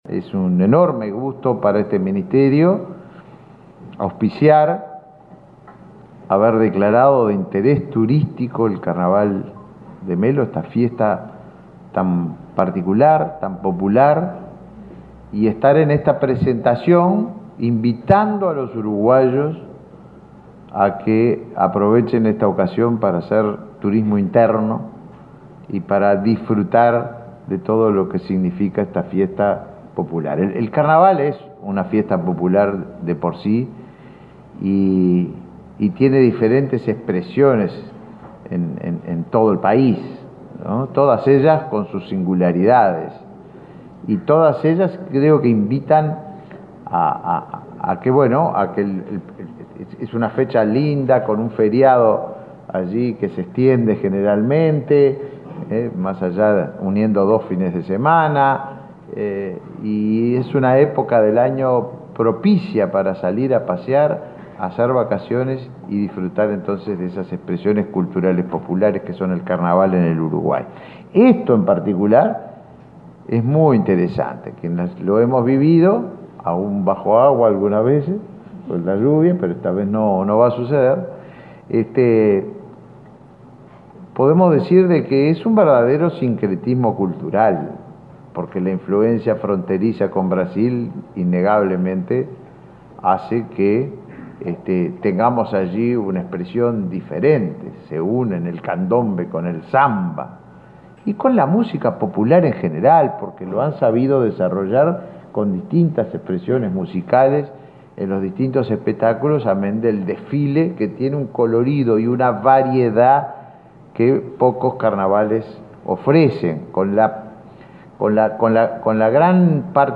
Palabra de autoridades en lanzamiento del Carnaval de Melo
El ministro de Turismo, Tabaré Viera, y la vicepresidenta, Beatriz Argimón, participaron del lanzamiento del Carnaval de Melo.